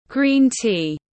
Trà xanh tiếng anh gọi là green tea, phiên âm tiếng anh đọc là /ˌɡriːn ˈtiː/
Green tea /ˌɡriːn ˈtiː/